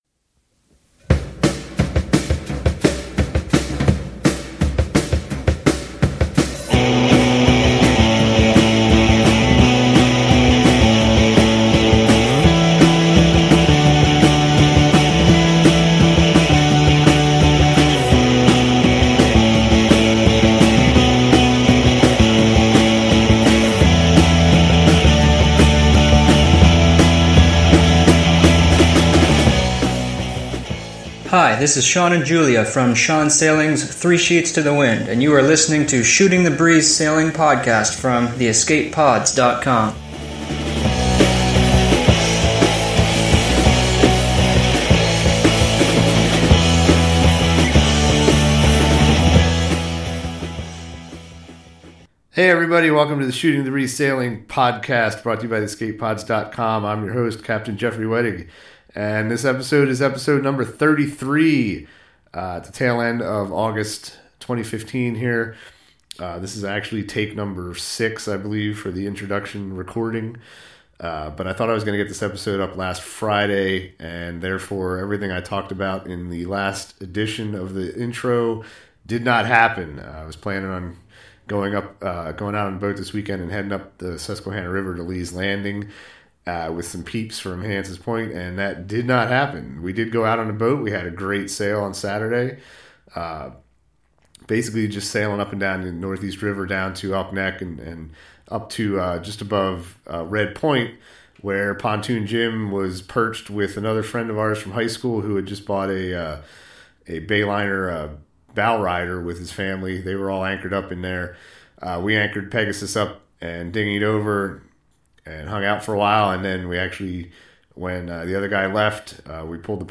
He interviewed me and then I interviewed him, and we chatted a bit in between.